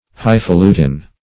highfalutin \high`fa*lu"tin\, highfaluting \high`fa*lu"ting\, n.